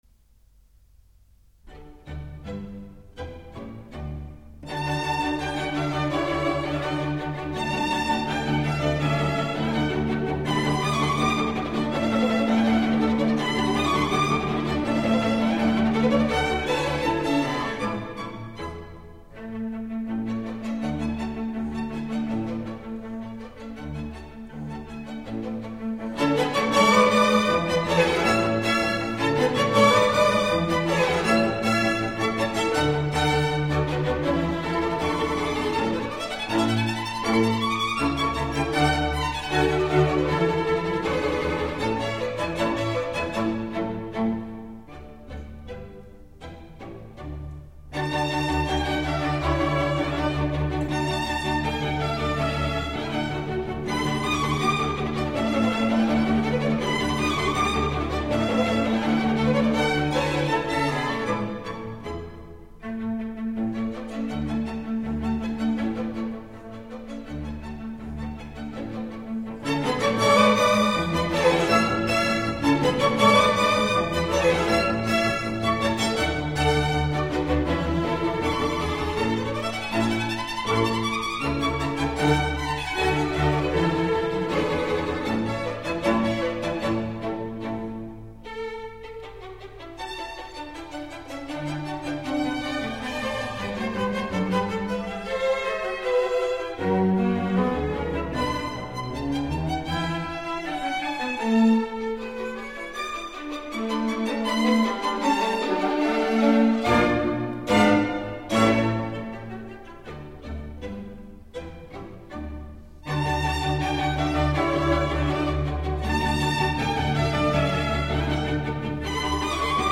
Presto